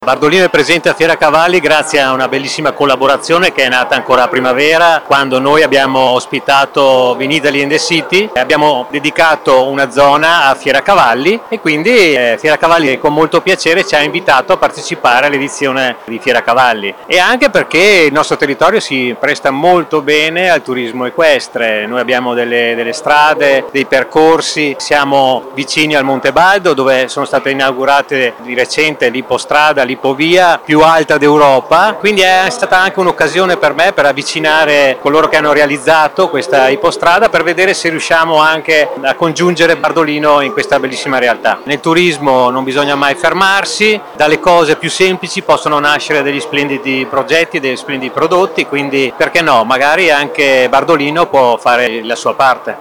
Ivan-De-Beni-sindaco-di-Bardolino-a-Fieracavalli.mp3